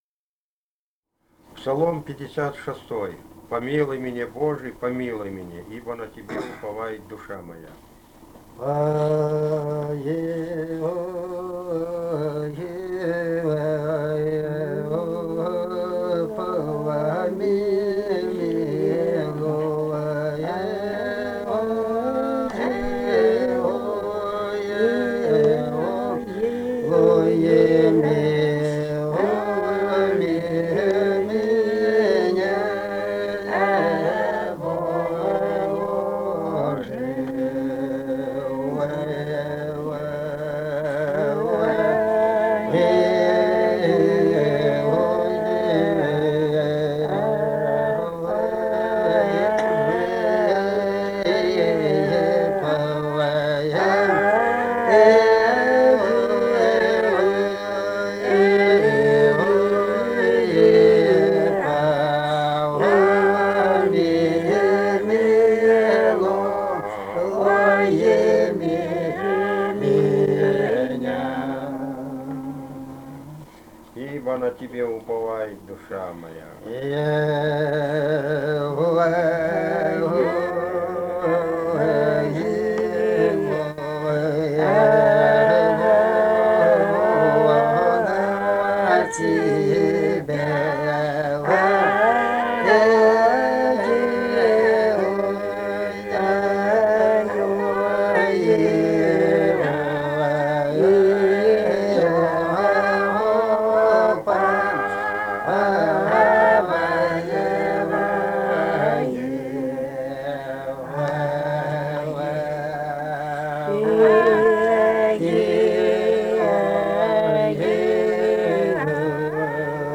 полевые материалы
Грузия, г. Тбилиси, 1971 г.